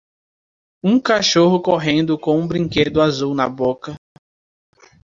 Pronúnciase como (IPA)
/koˈʁẽ.du/